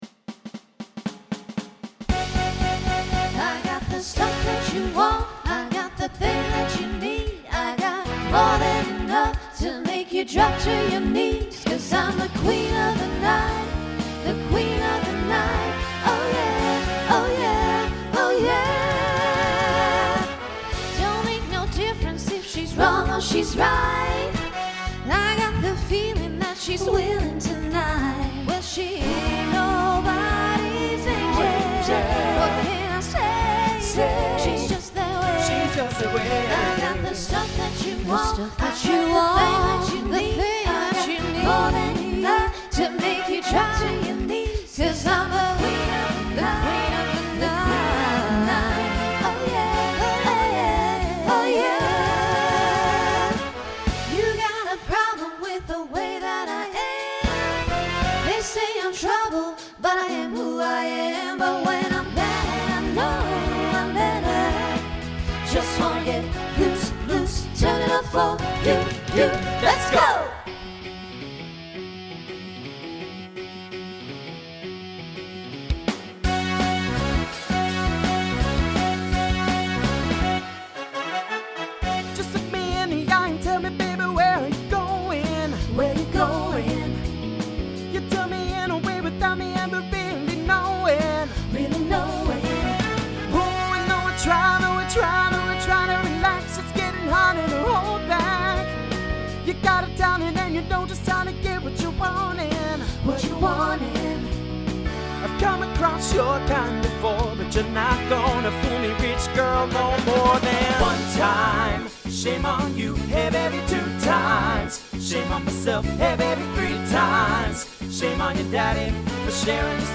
SSA/TTB/SATB
Voicing Mixed Instrumental combo Genre Pop/Dance